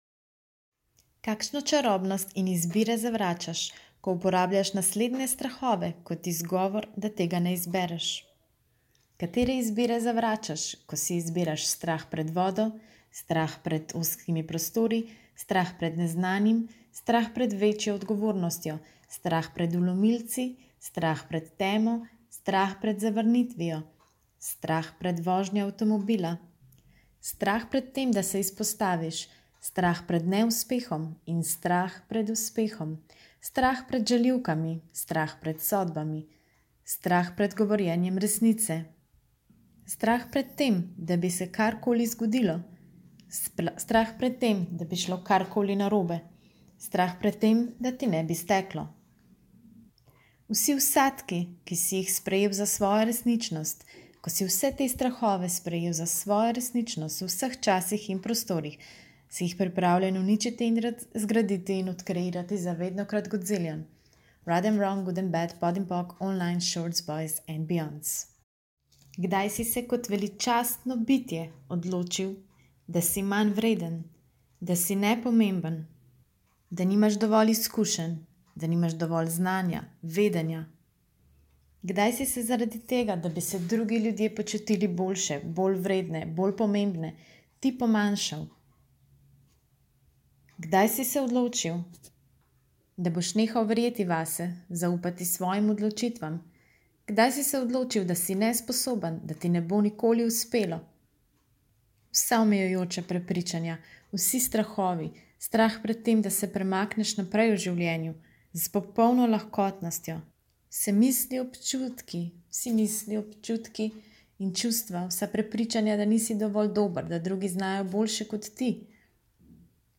P.S.: Ko sem ga do konca skreirala, sem na koncu ugotovila, da na nekaterih minutah nekaj šumi v ozadju, še posebej na koncu.